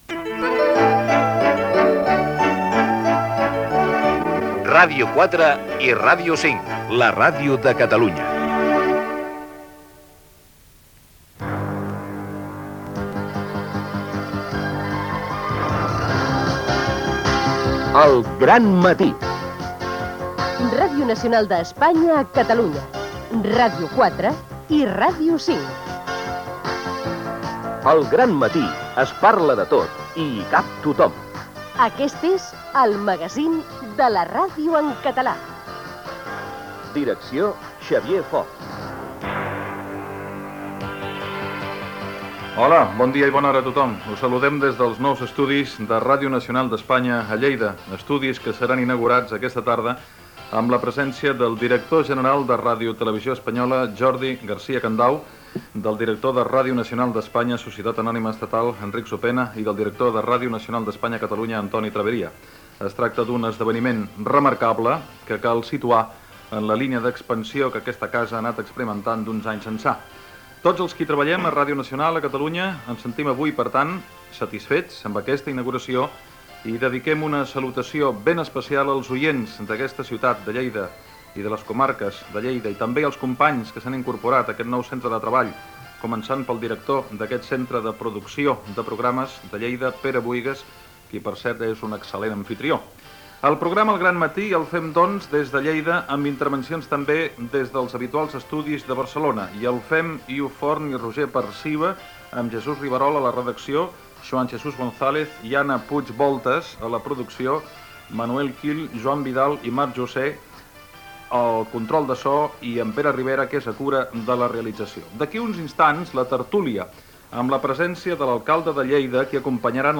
Espai fet des dels nous estudis de Radio Nacional de España a Lleida (que s'inauguren aquella tarda).
Info-entreteniment